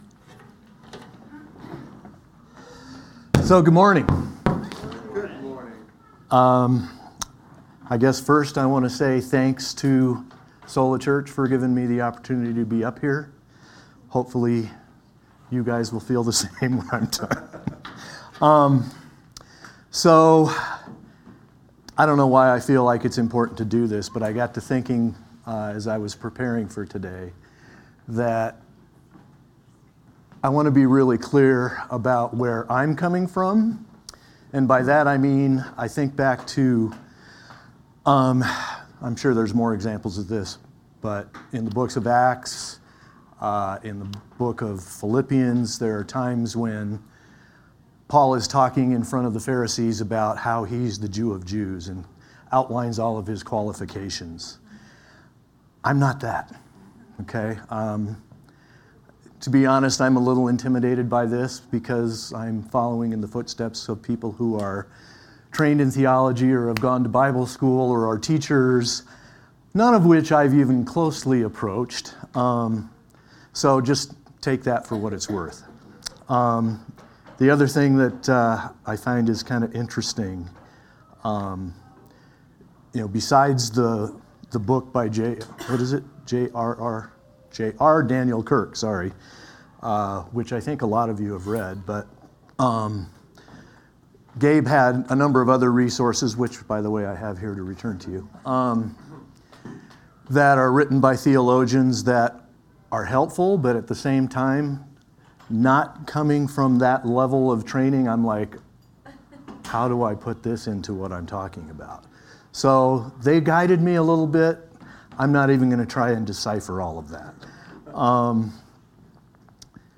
Sermons from Sunday Gatherings at Sola Church